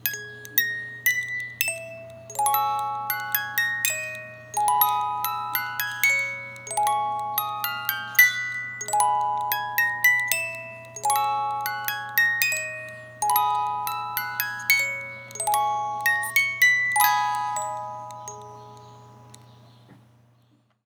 MUSIC BOX.wav